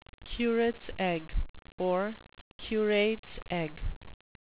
curate's egg (KYOOR-itz eg) noun
Pronunciation: